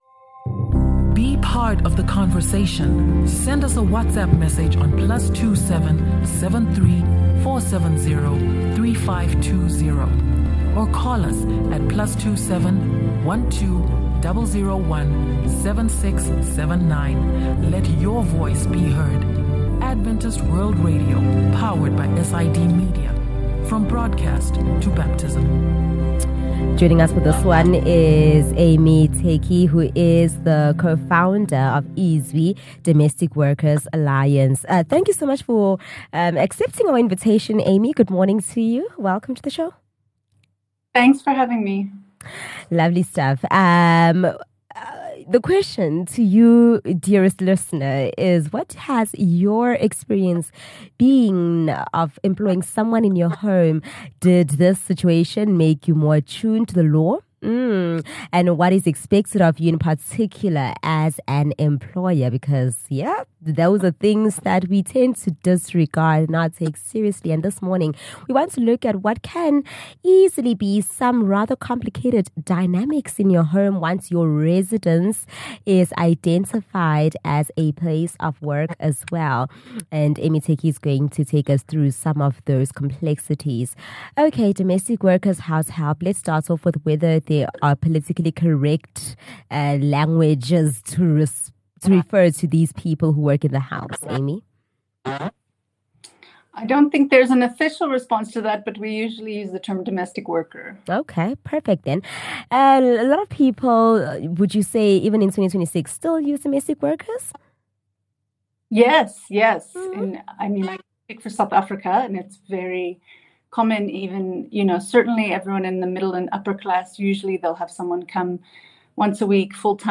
Once you have someone working in your home and you pay that person, your house becomes a place of work, and you need to abide the legal frameworks that govern and protect both employees and employers. This conversation enlightens you on some of those important issues of consideration.